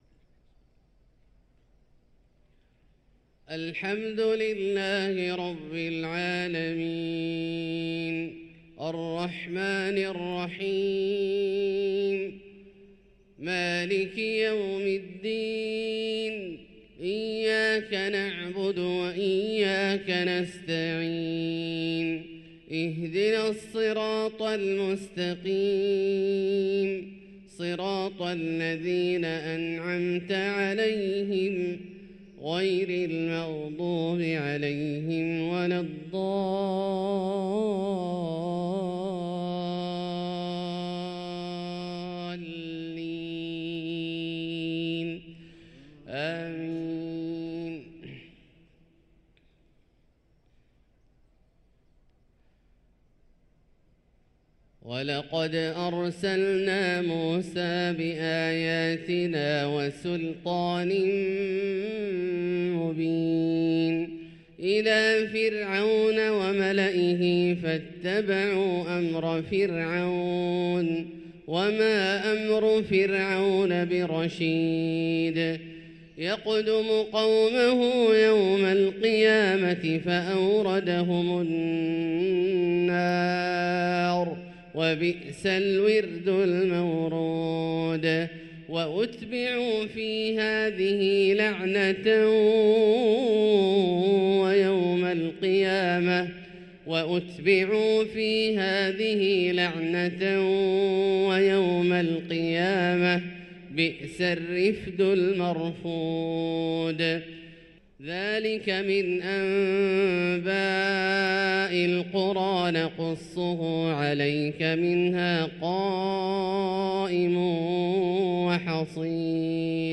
صلاة الفجر للقارئ عبدالله الجهني 13 ربيع الأول 1445 هـ
تِلَاوَات الْحَرَمَيْن .